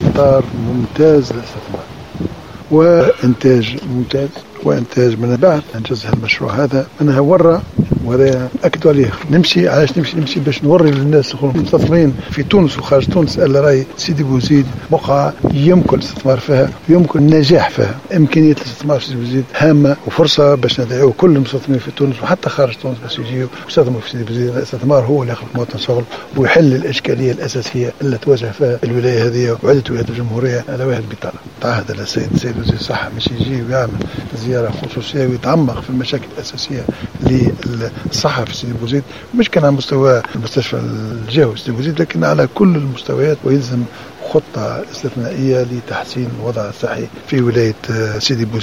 وأكد الصيد في تصريحات صحفية اليوم من ولاية سيدي بوزيد أن اهتمام وزير الصحة يجب أن لا يقتصر على المستشفى الجهوي بل يكون شاملا وعلى جميع المستويات بالجهة.